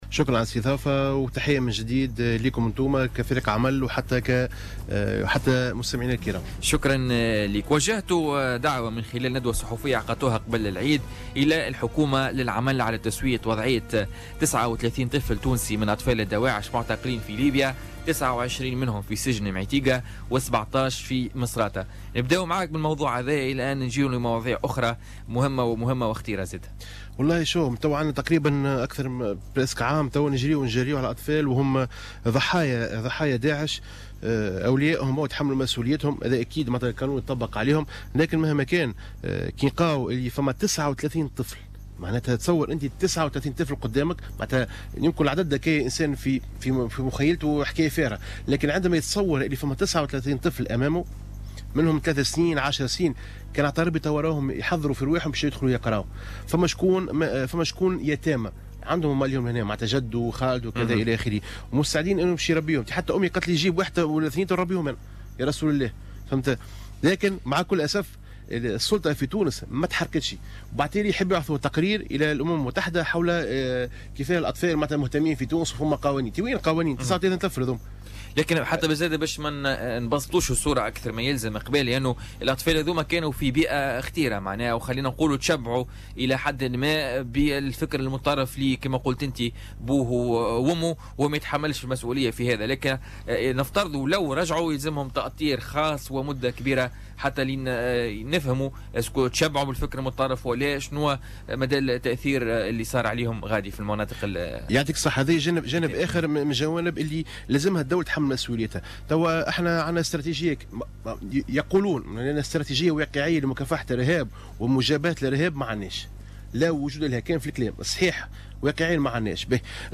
ضيف بولتيكا